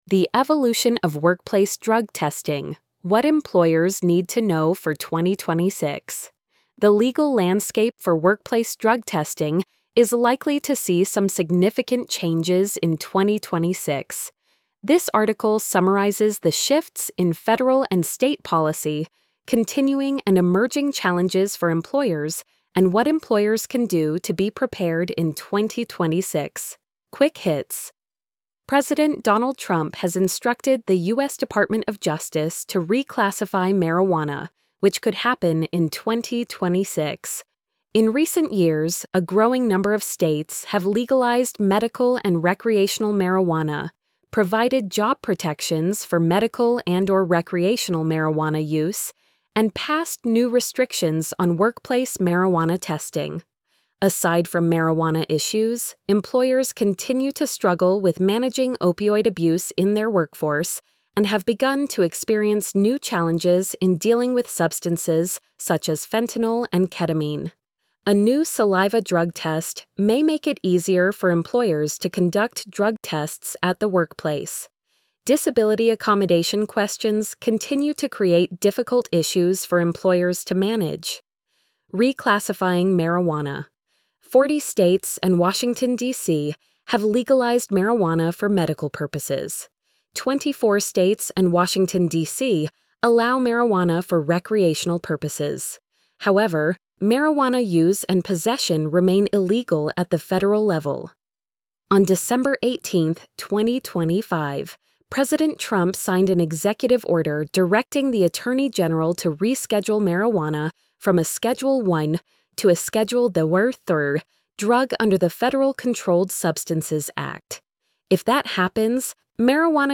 the-evolution-of-workplace-drug-testing-what-employers-need-to-know-for-2026-tts.mp3